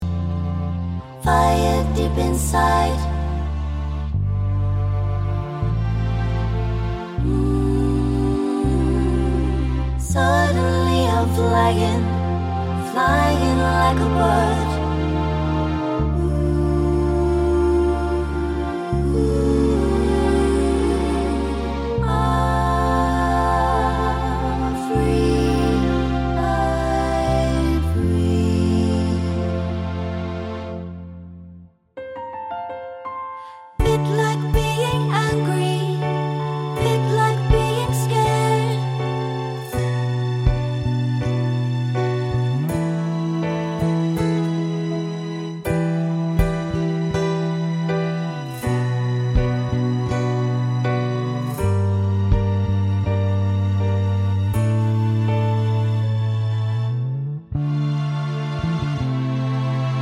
no Backing Vocals Musicals 4:14 Buy £1.50